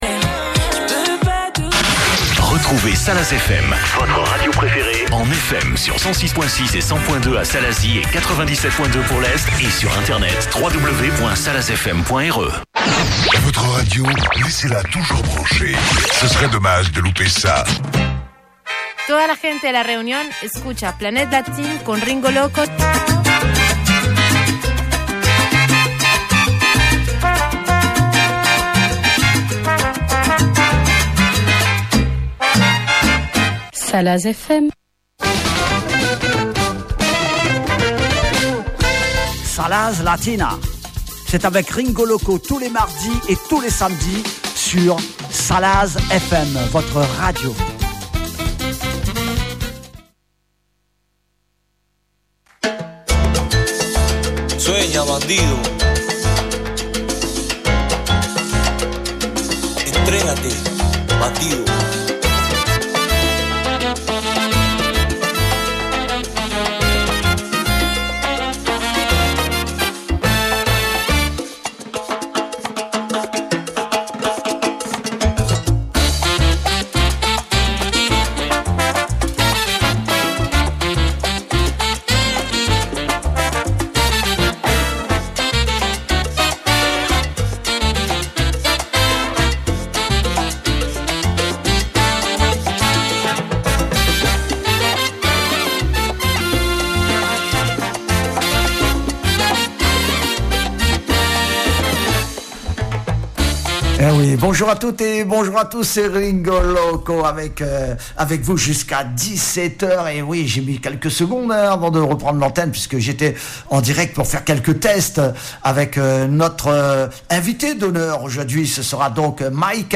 ECOUTEZ VOTRE EMISSION SALSA EN PODCASTS